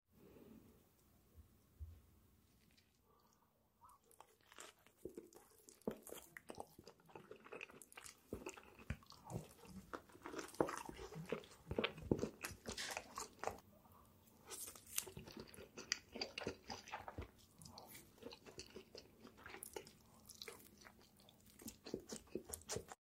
Spicy Pork Ribs & Spicy Fire Noodles ASMR MUKBANG! Satisfying Big Bites